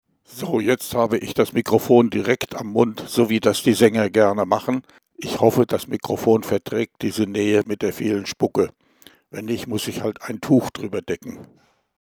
Gleiche Aufnahme wie zuvor: Mikrofon am Mund, aber Töne unter 300 Hz entfernt (mp3)
Hier sind jetzt zwar die tiefen Töne einigermaßen glaubhaft reduziert, aber die fauchenden oder zischenden Nebengeräusche sind so nicht zu beseitigen. Insgesamt erinnert die Stimmenfärbung an Ansagen auf dem Jahrmarkt.
Mikro_Baesse_weg_300Hz.mp3